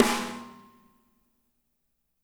-MEDSNR2I -L.wav